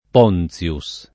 p0nZLuS] cogn. — forma latinizz., poi a sua volta italianizz. in Ponzio [p0nZLo], del cogn. sp. Ponce (es. il teologo Basilio P. de León, 1569-1629) e del cogn. fr. Du Pont (es. l’incisore Paul du P., 1603-58)